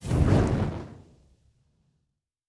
Weapon 14 Shoot 2 (Flamethrower).wav